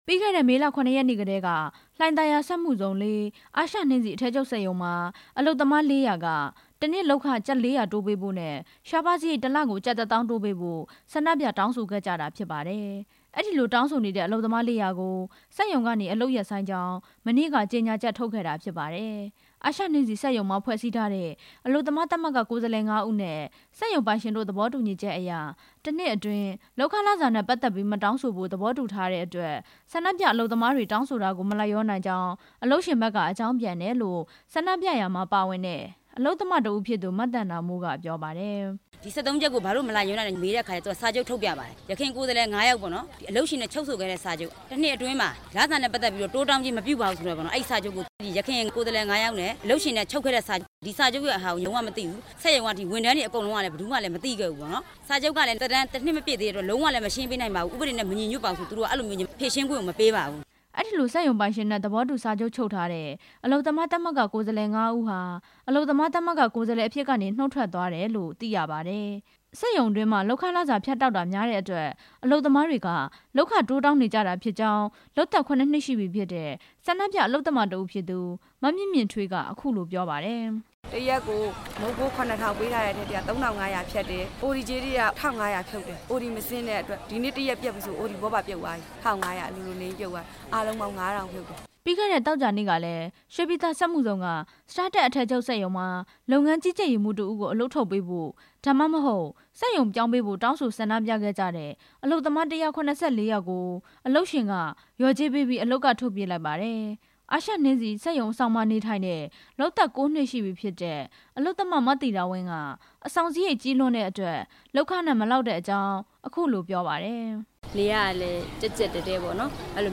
ရွှေစွမ်းရည် ပီနန်အိတ်စက်ရုံ အကြောင်း တင်ပြချက်